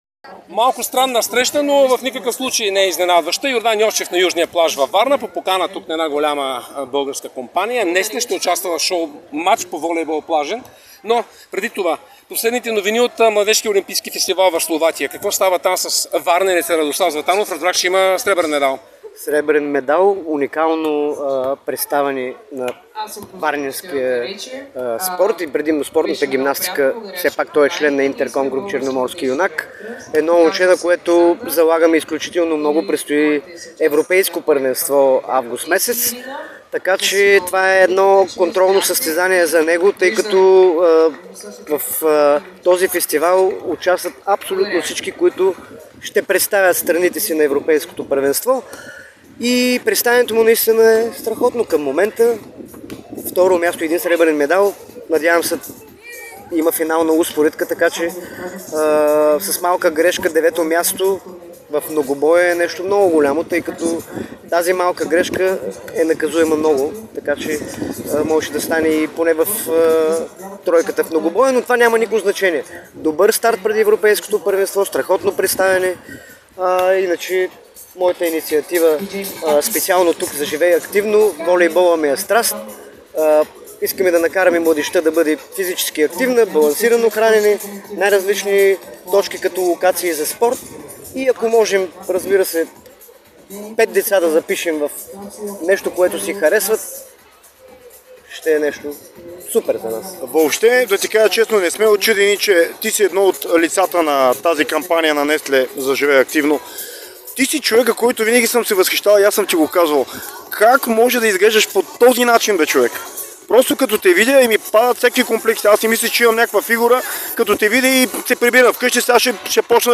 Той говори пред Дарик радио и dsport за това как поддържа невероятната си спортна форма. Йовчев заяви, че трябва ежедневно да се съпротивляване с възрастта и даде някои съвети за здравословно хранене.